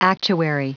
Prononciation du mot actuary en anglais (fichier audio)
Prononciation du mot : actuary